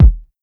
MB Kick (15).wav